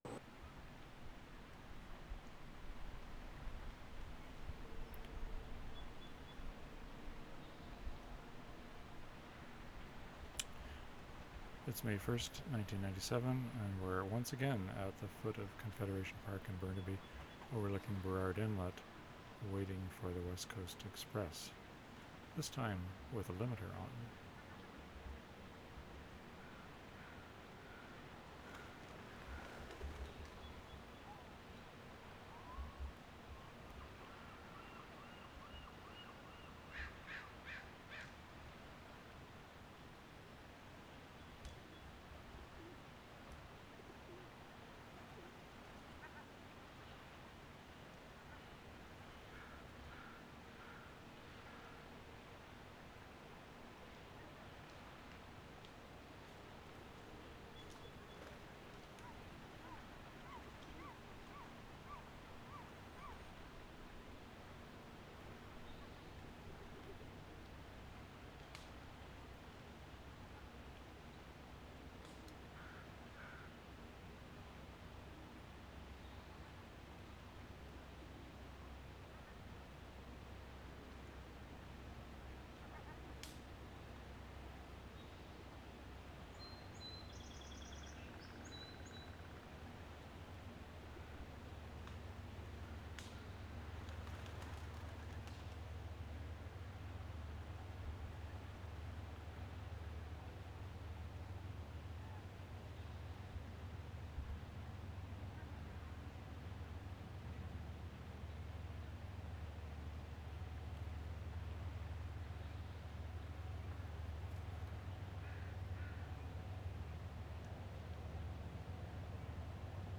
West Coast Express middle distance 2:30
6. Same location, tape ID with limiter on. 2:20 train whistle in middle distance.